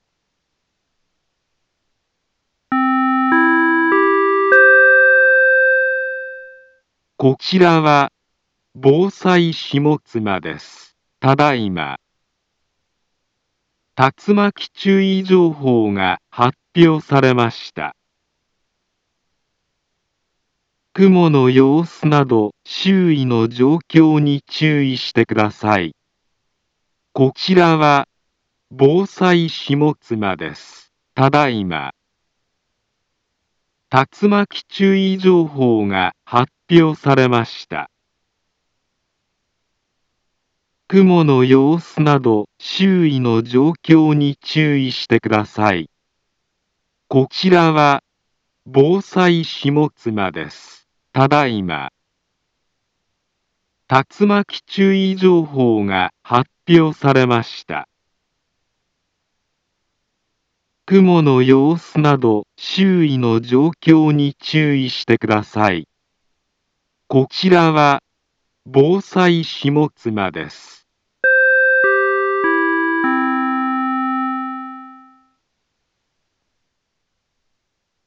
Back Home Ｊアラート情報 音声放送 再生 災害情報 カテゴリ：J-ALERT 登録日時：2025-08-08 18:34:39 インフォメーション：茨城県北部、南部は、竜巻などの激しい突風が発生しやすい気象状況になっています。